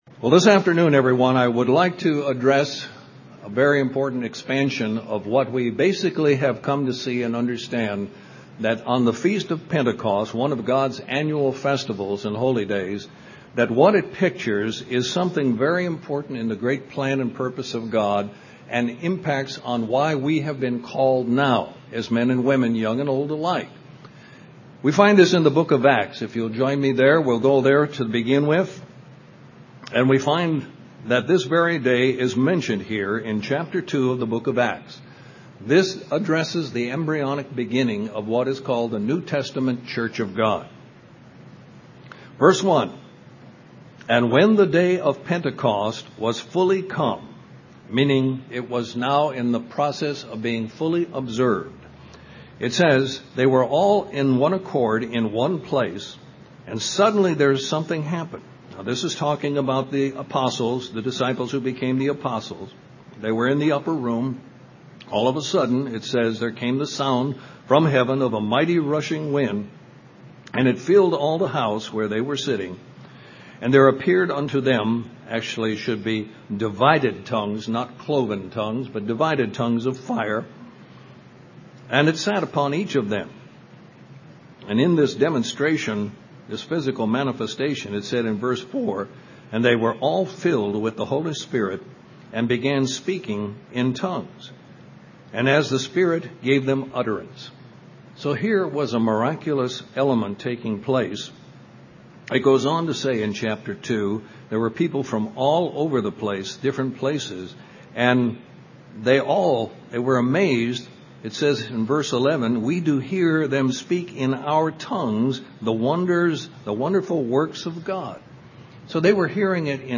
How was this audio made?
Given in Columbus, GA Central Georgia